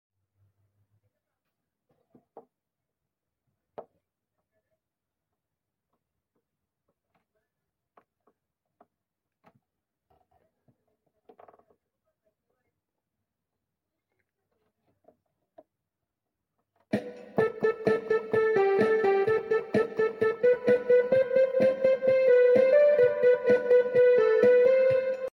Tutorial